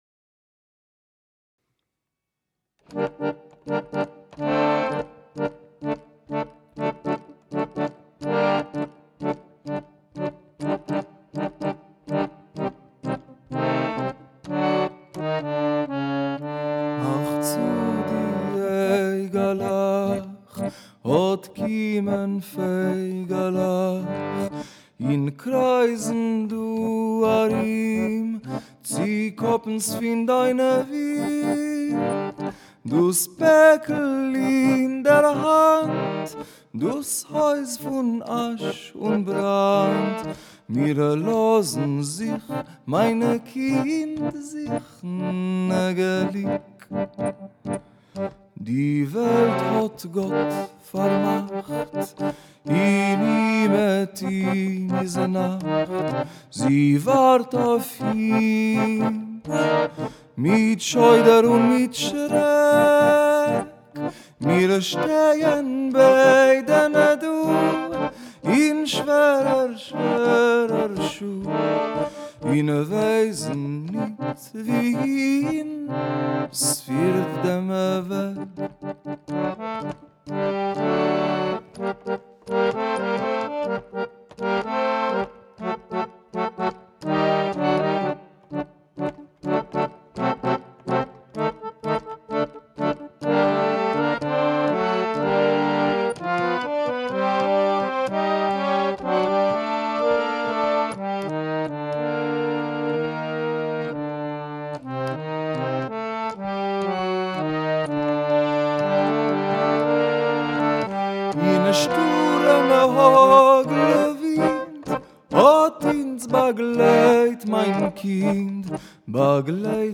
In memory and honor of these people, we decided to sing the song “Close Your Little Eyes” in the culture house.
harmonica